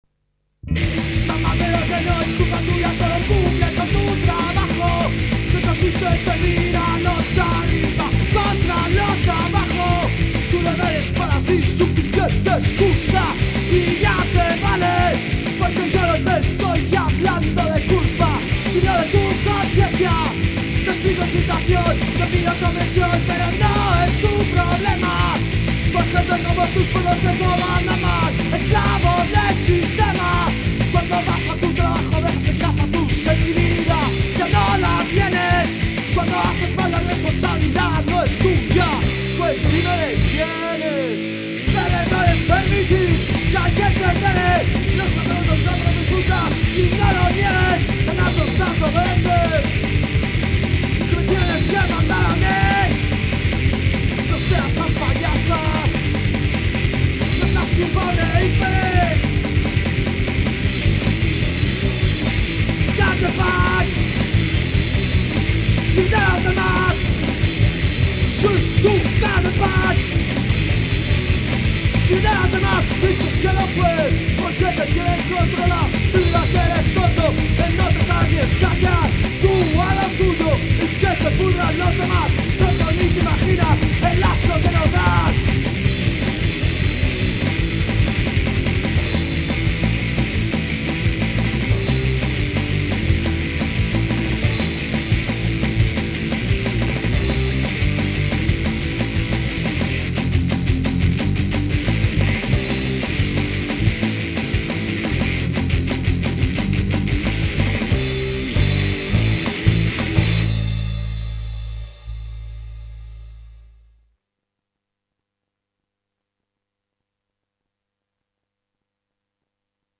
voz y guitarra
batería
punk